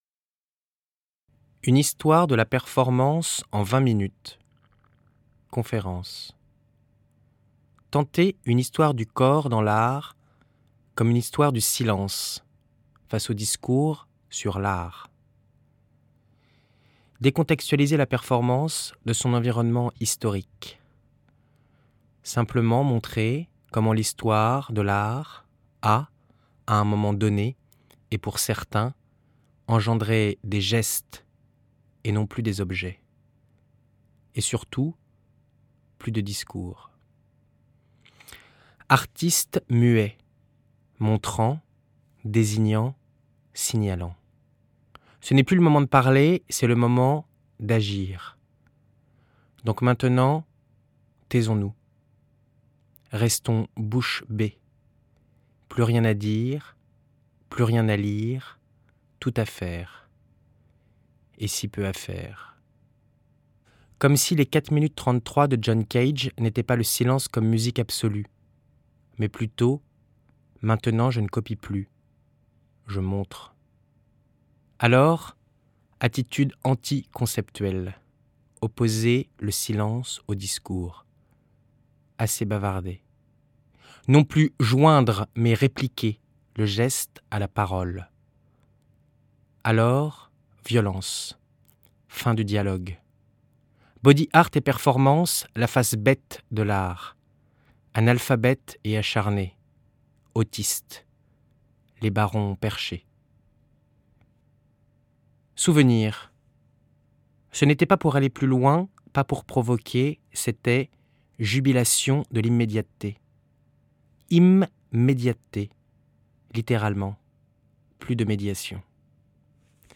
Conférence imaginaire
Montage et bruitage